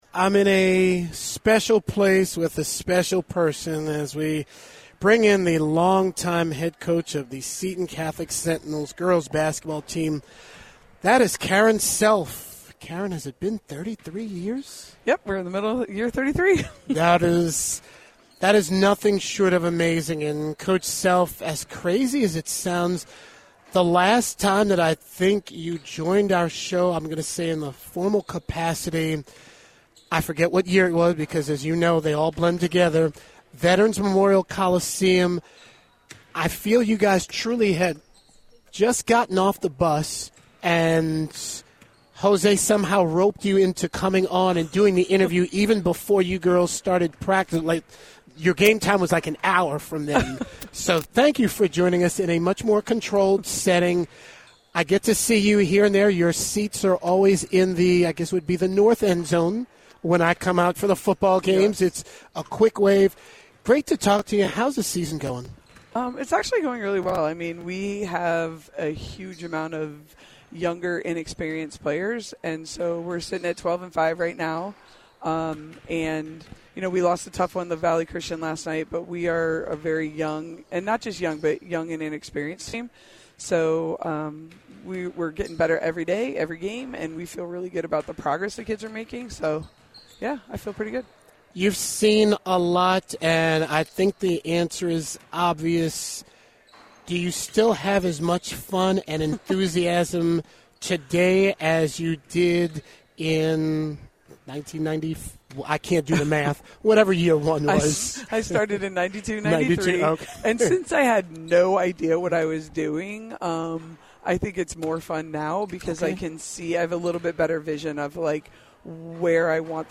Radio show interview: click here